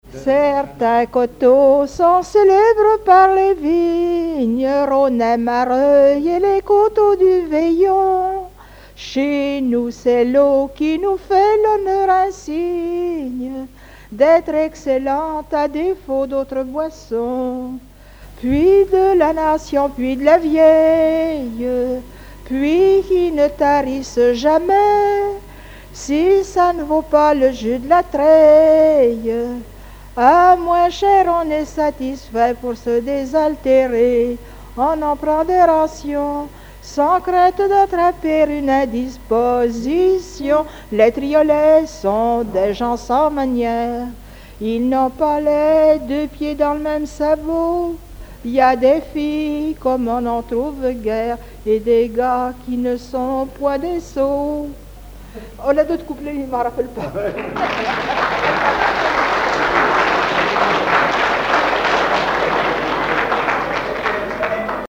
Regroupement de témoins ; chanteuses, chanteurs, musiciens
Catégorie Pièce musicale inédite